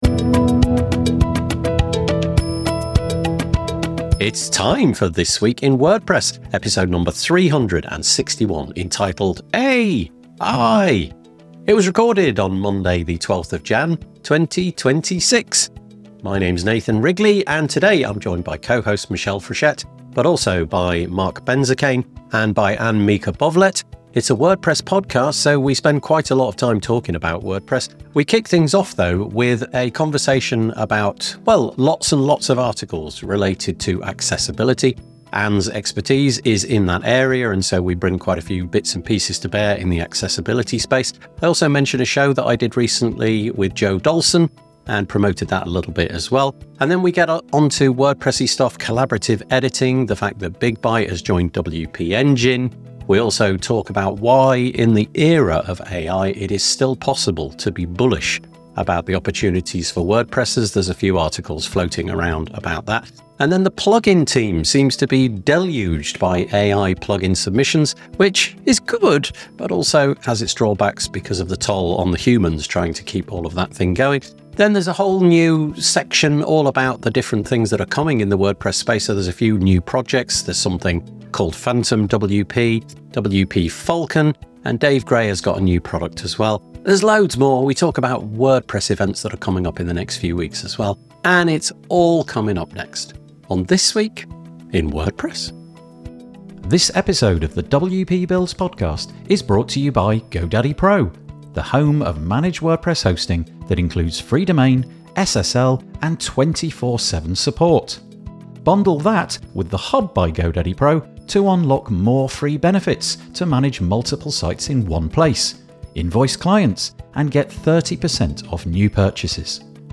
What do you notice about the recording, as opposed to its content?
Come and join us LIVE for the This Week in WordPress show.